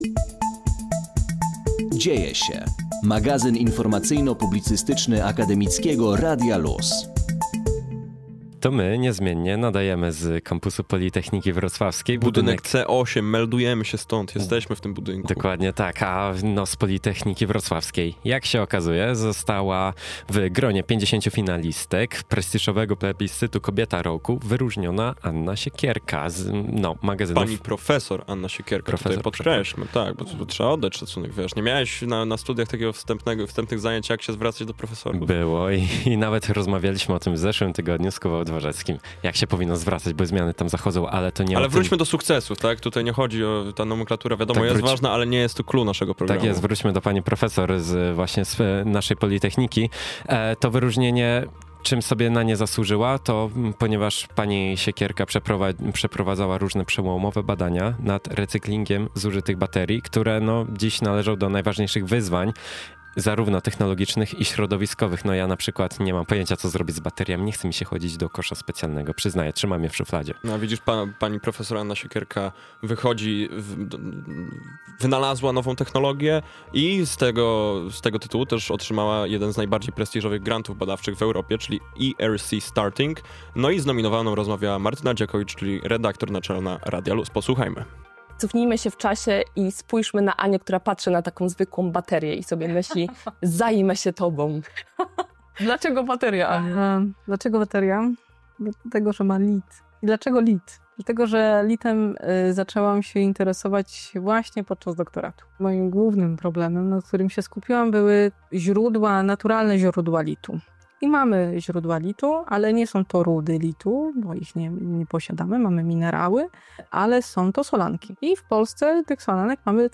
Tak mówiliśmy o tym sukcesie w poniedziałkowym wydaniu audycji Dzieje się na antenie Akademickiego Radia LUZ: